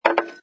sfx_put_down_glass07.wav